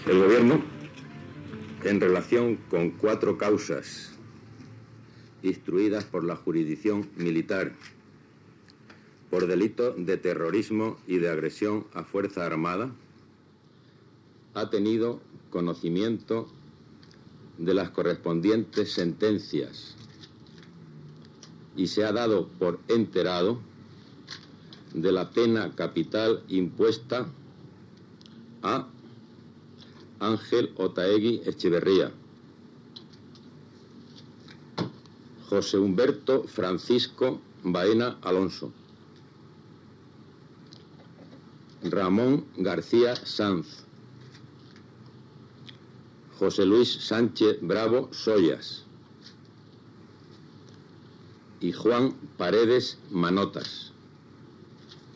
Anunci del ministre d'Informació i Turisme León Herrera que el Consell de Ministres espanyol es donava per "enterado" de les cinc condemnes a mort a l'endemà de tres militants del FRAP, Xosé Humberto Baena Alonso, José Luis Sánchez Bravo i Ramón García Sanz, i dos militants d`'ETA, Juan Paredes Manot i Ángel Otaegui Etxeverria.
Informatiu